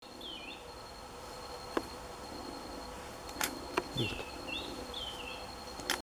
Bailarín Oliváceo (Schiffornis virescens)
Fase de la vida: Adulto
Localidad o área protegida: Reserva Privada y Ecolodge Surucuá
Condición: Silvestre
Certeza: Vocalización Grabada